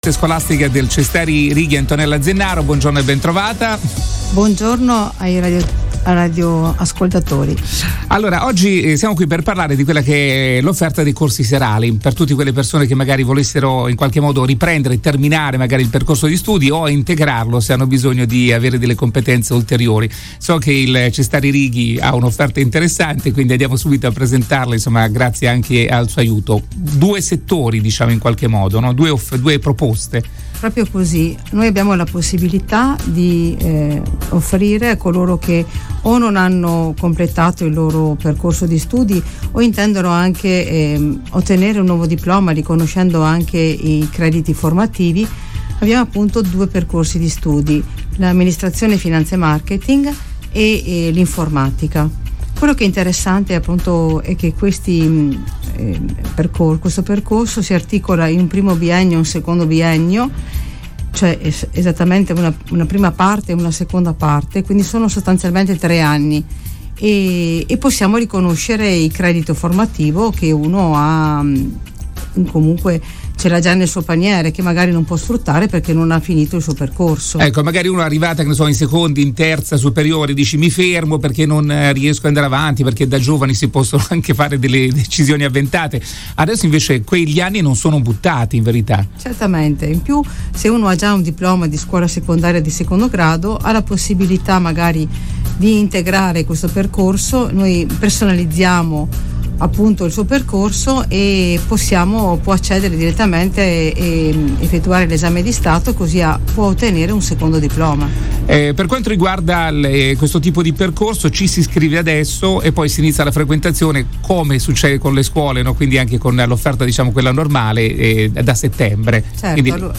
SPOT RADIO
serale_spot-radio_2022.mp3